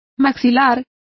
Complete with pronunciation of the translation of jawbones.